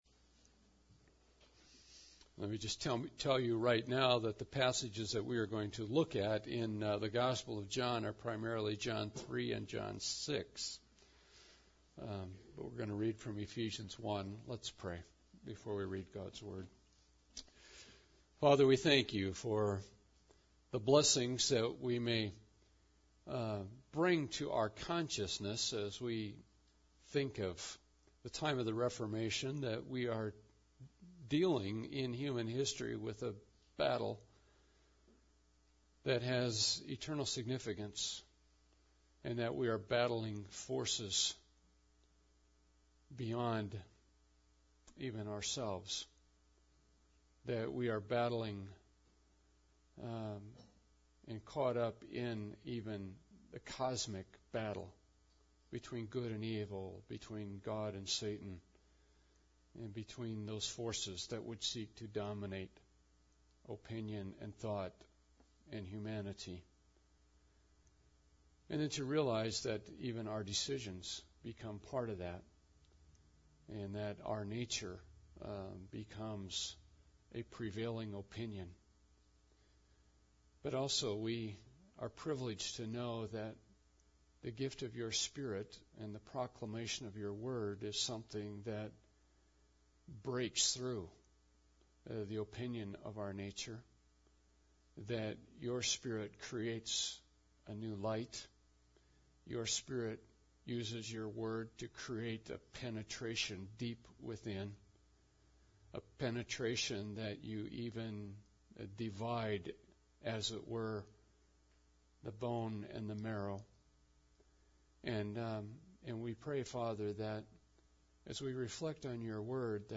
Should We Soften Election – Reformation Service